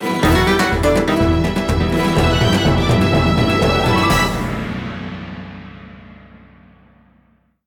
Higher quality rip from the Wii U version.